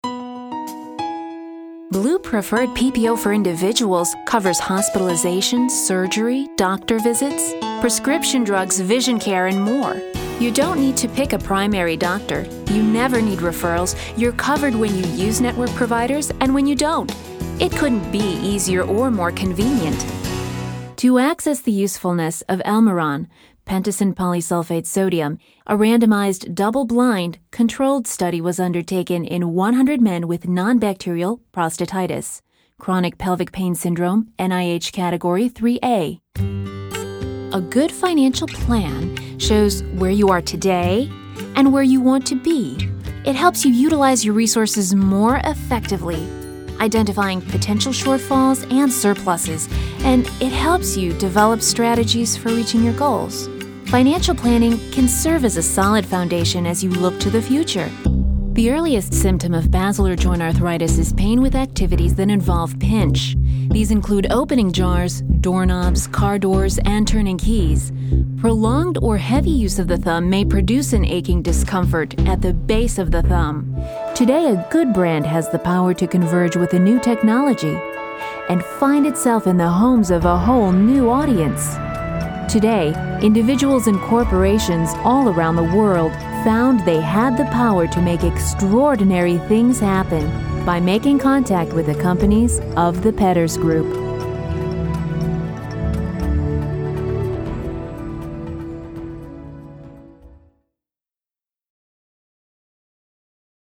voiceover demo
Narrative.mp3